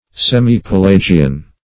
Semi-Pelagian \Sem`i-Pe*la"gi*an\, a.